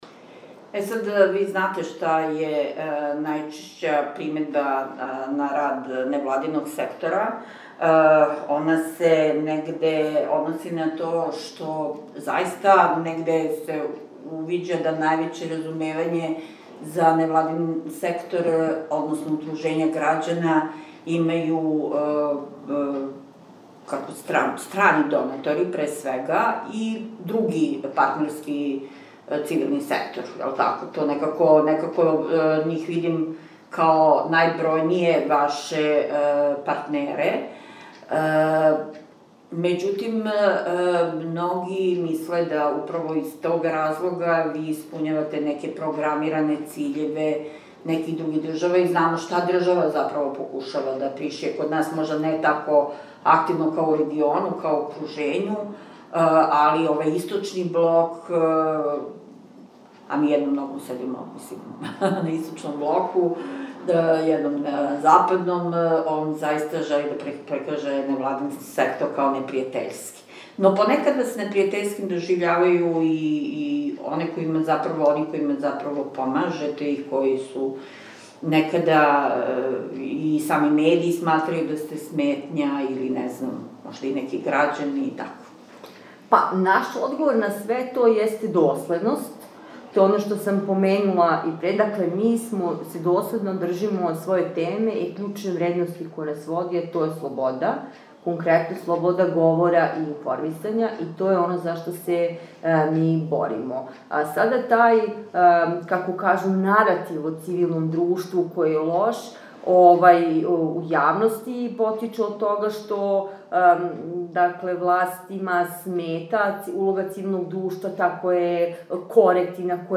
Шумадијски центар за грађански активизам „Рес публика“ обележио је ових дана 10 година постојања мало радно, мало свечано кроз Конференцију посвећену радном и професионалном статусу жена новинарки, изложбу о новинаркама на радном задатку и доделом захвалница својим најближим сарадницима. О активизму и њиховом раду разговарали смо